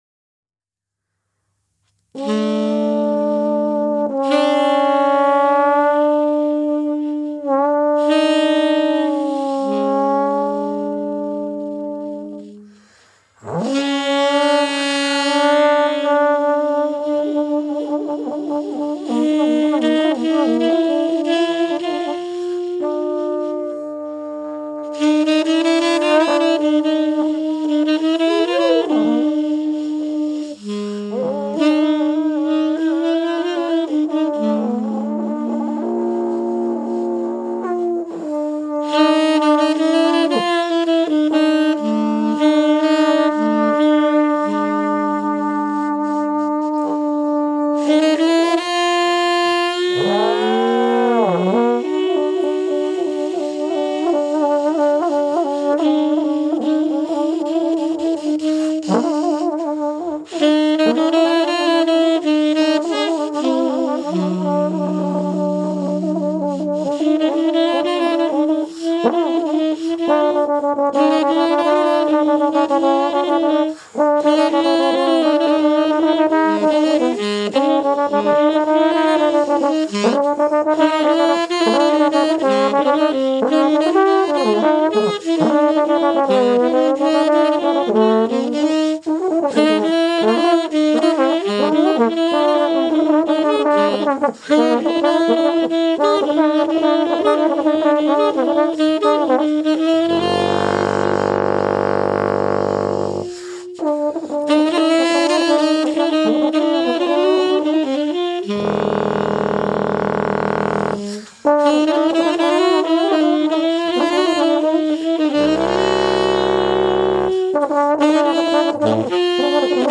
Tenorsaxophon
im Atelier